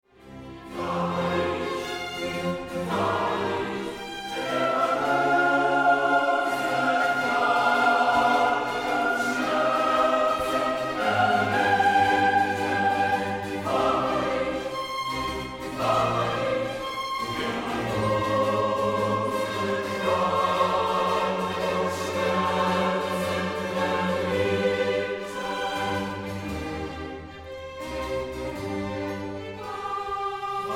Erbaulich, feierlich und ehrwürdig